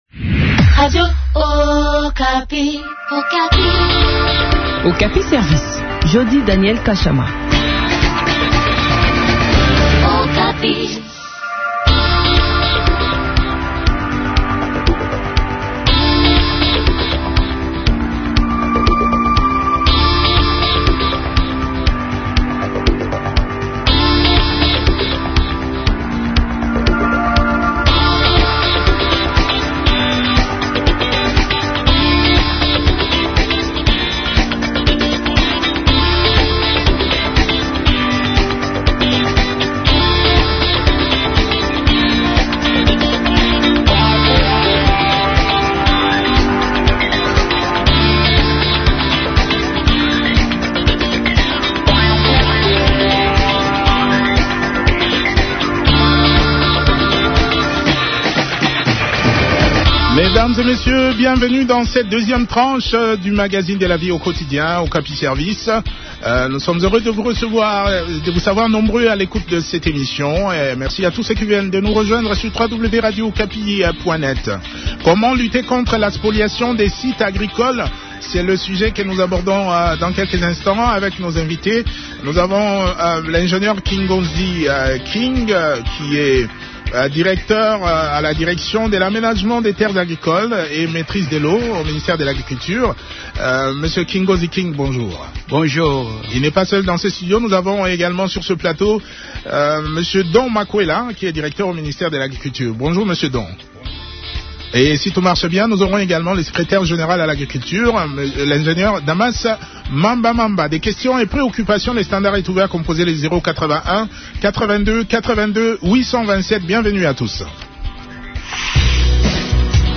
lors d’une émission « Okapi Service », à Kinshasa.